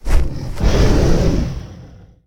combat / creatures / dragon / he / attack2.ogg